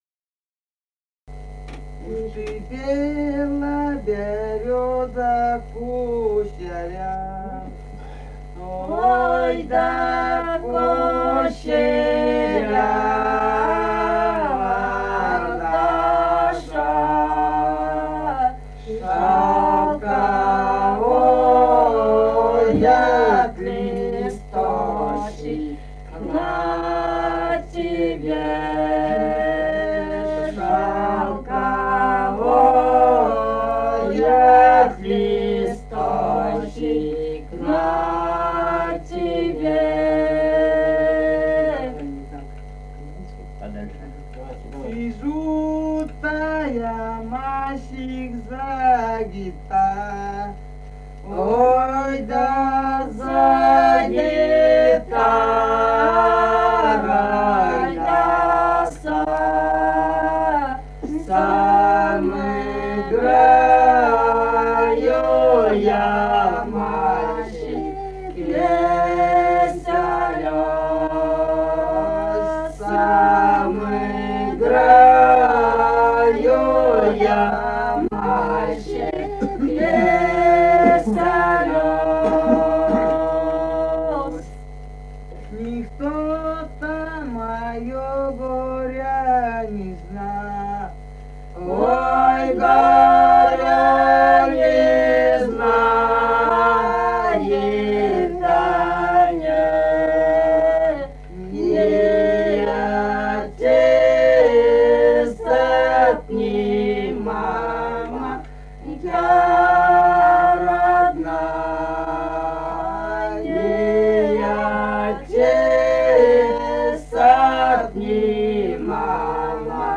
Республика Казахстан, Восточно-Казахстанская обл., Катон-Карагайский р-н, с. Фыкалка, июль 1978.